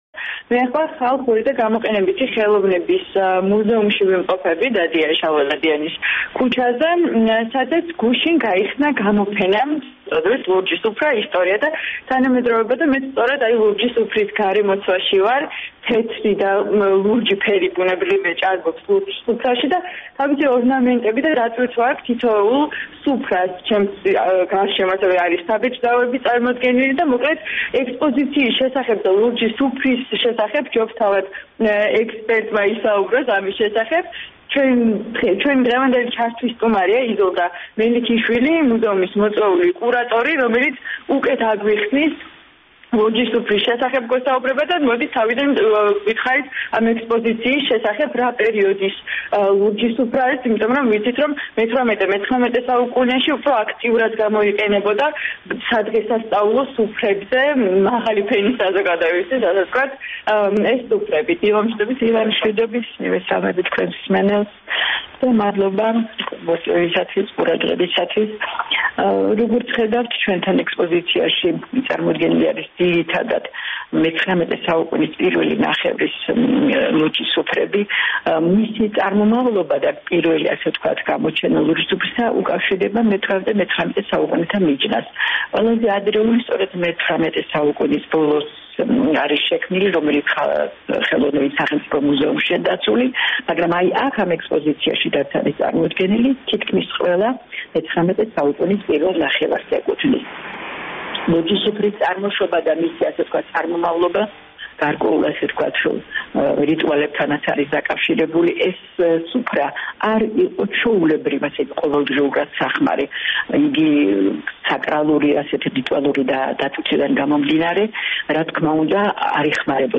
ხალხური და გამოყენებითი ხელოვნების მუზეუმის გამოფენაზე წარმოდგენილია მეცხრამეტე საუკუნეში შექმნილი ლურჯი სუფრები, საბეჭდავები, დაჩითული ქსოვილების ნიმუშები, აღმოსავლური მანები... თავისუფლების დილის“ ჩართვის სტუმარი